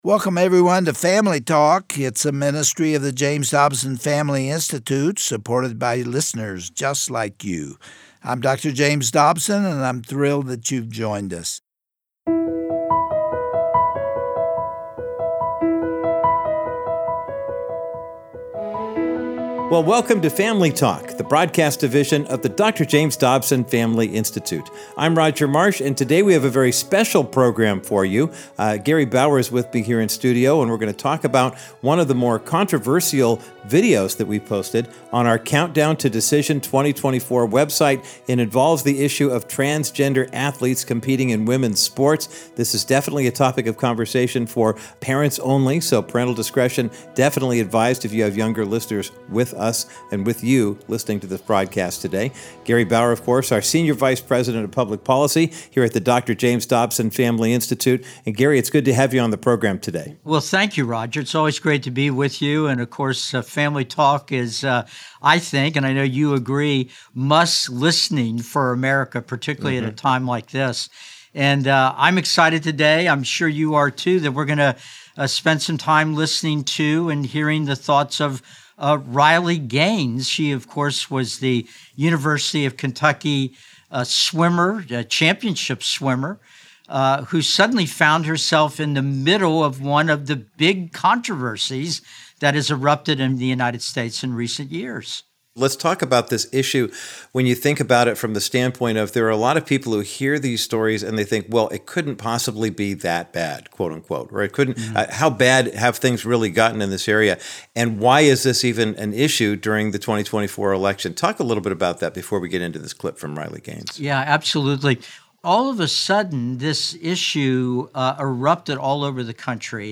She is a 12-time NCAA All-American swimmer who competed against and was tied with a biological male at an NCAA freestyle championship. Today on Family Talk, Gary Bauer talks with Riley about this unthinkable situation.